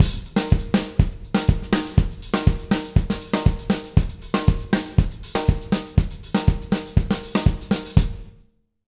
120ZOUK 04-R.wav